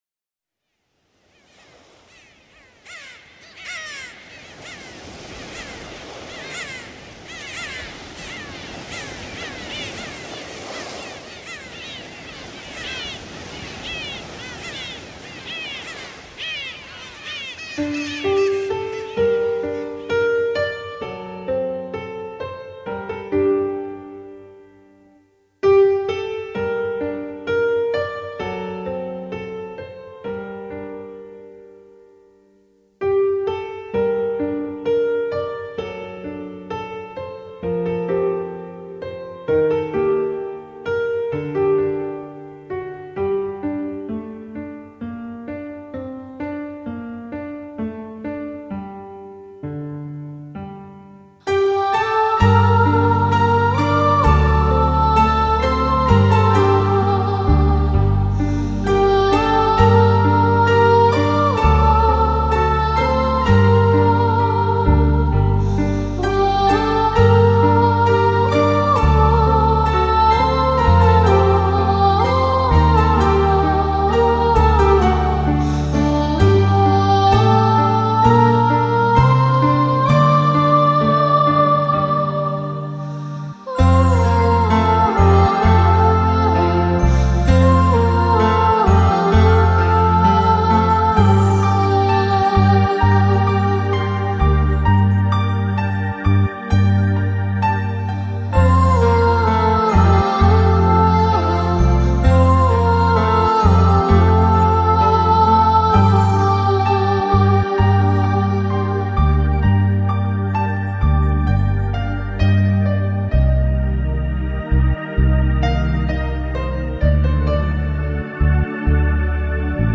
極具空間感的清亮嗓音，在曼妙的琴音之中得到了無邊無際的釋放